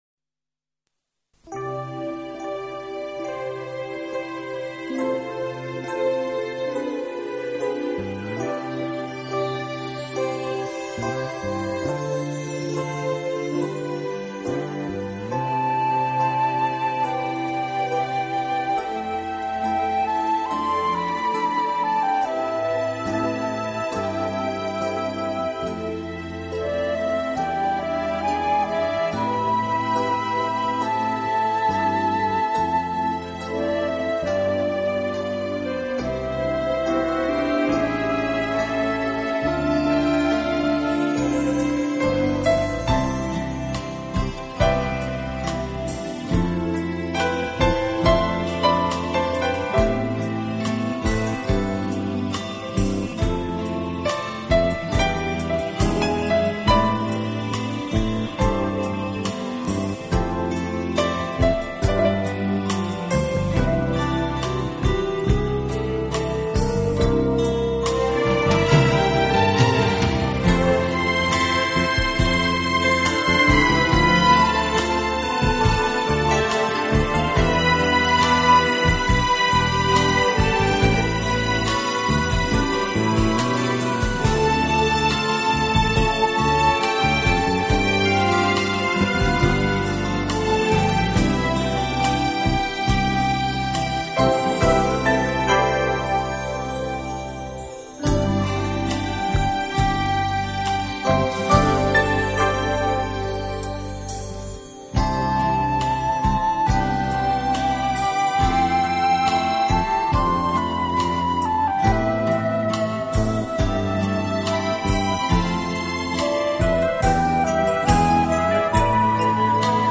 浪漫纯音乐
经典歌曲等，经不同乐器演奏，谱写出一个个浪漫的诗